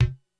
Index of /90_sSampleCDs/300 Drum Machines/Korg DSS-1/Drums01/06
Kick 02.wav